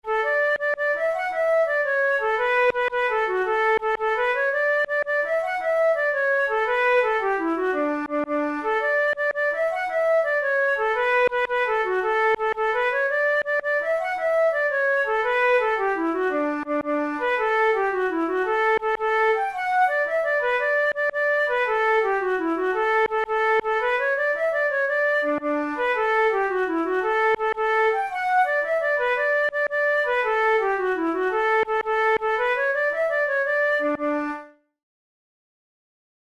InstrumentationFlute solo
KeyD major
Time signature6/8
Tempo112 BPM
Jigs, Traditional/Folk
Traditional Irish jig